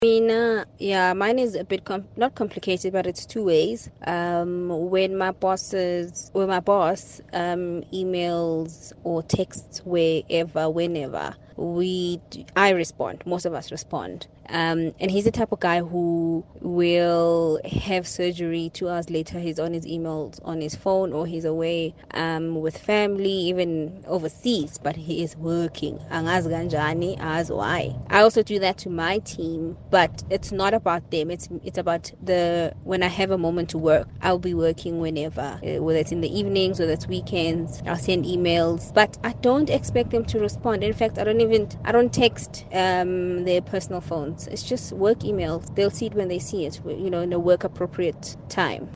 Kaya Drive listeners share that their employers contact staff after hours: